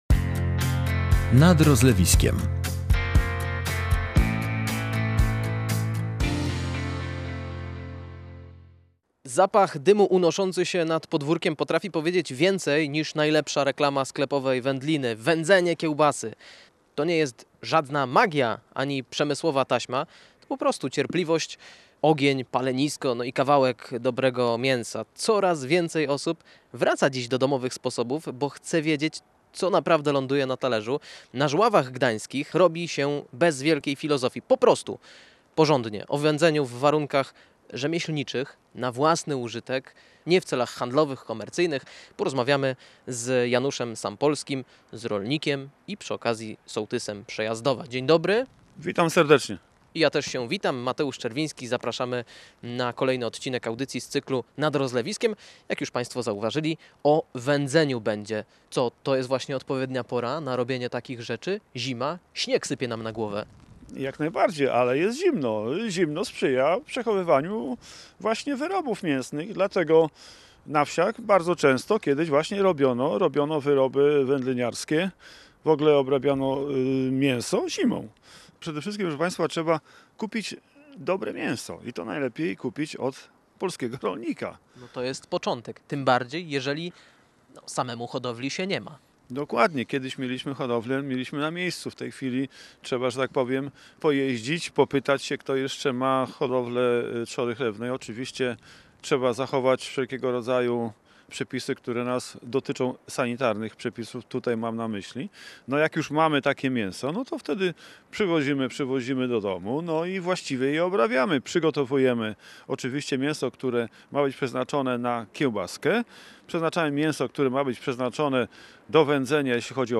rozmawia z rolnikiem i sołtysem Przejazdowa